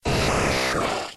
Cri d'Abra dans Pokémon X et Y.